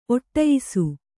♪ oṭṭayisu